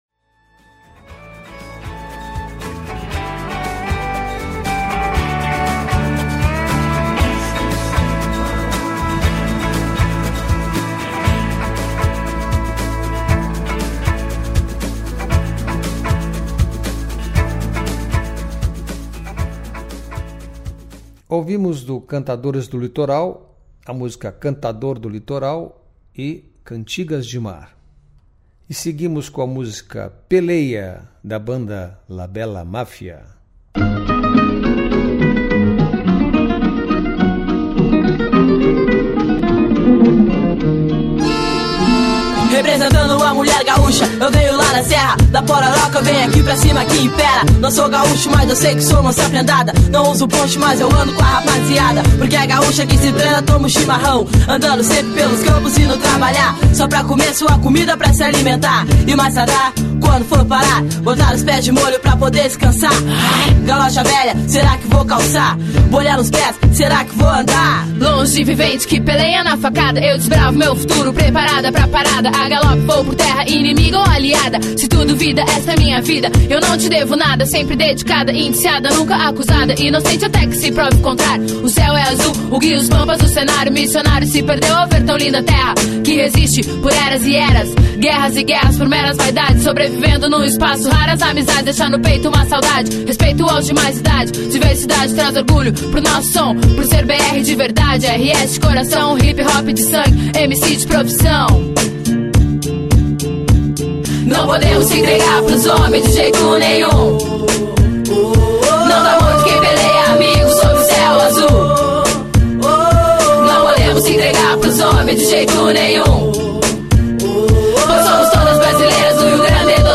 O programa de hoje é dedicado à música afro do Rio Grande do Sul e do Uruguai.